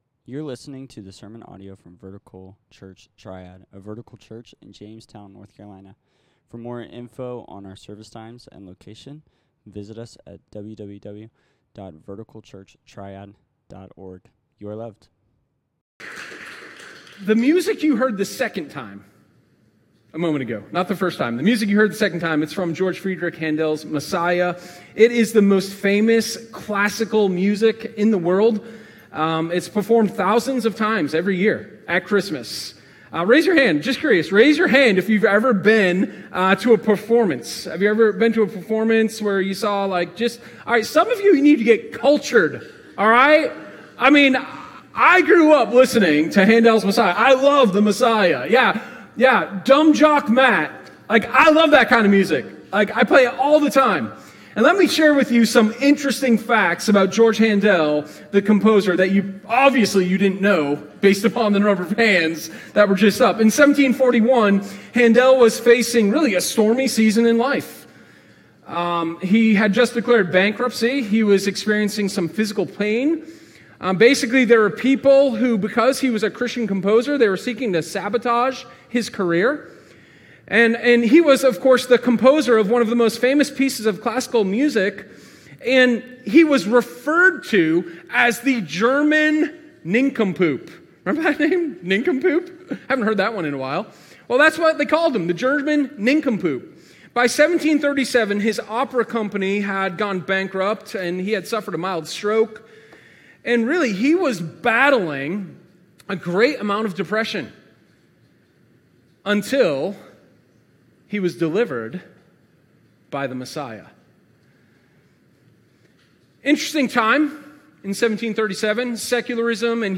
Sermon12_12_-Mighty-God.m4a